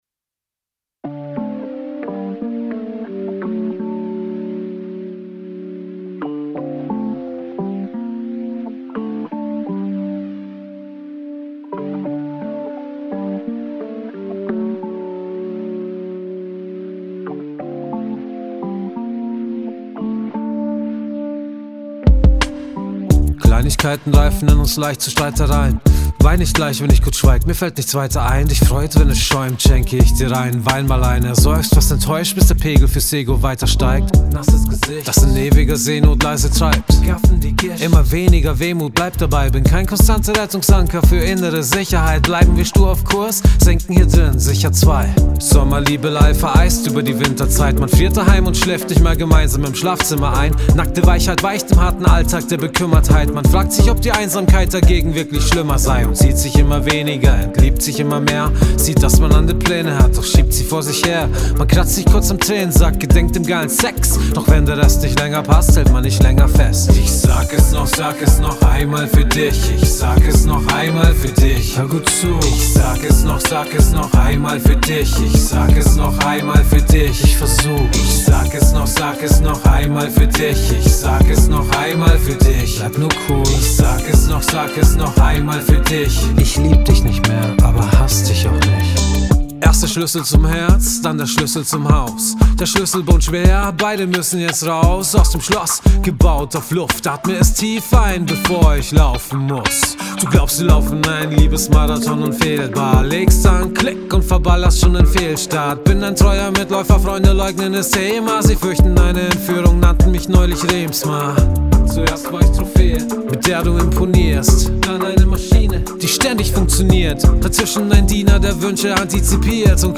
Rap mit Amerikaner
:D Ich habs nochmal etwas fetter und transparenter gemacht, meine ich jedenfalls.
Die knallt zu doll rein und wird anstrengend.